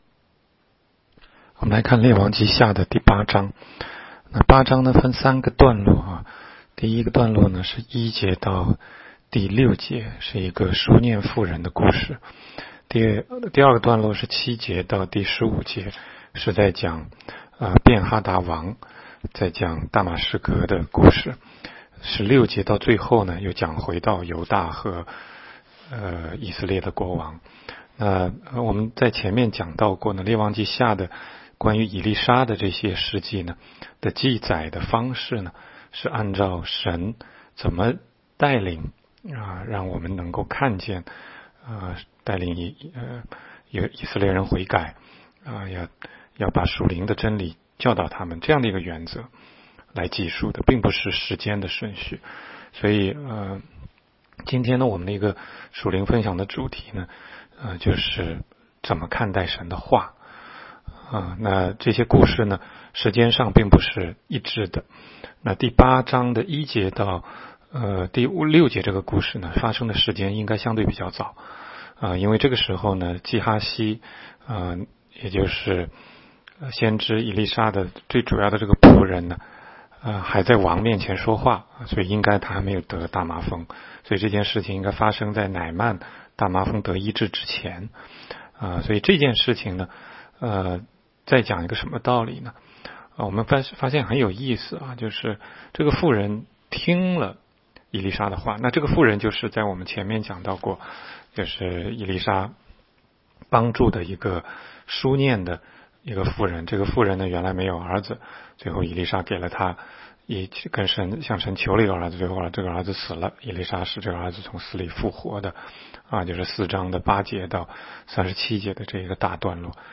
16街讲道录音 - 每日读经-《列王纪下》8章